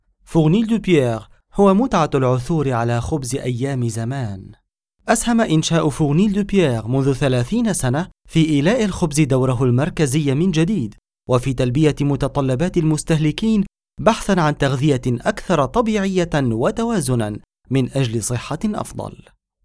Native standard Arabic voice, believable, narrative, and warm
Sprechprobe: Werbung (Muttersprache):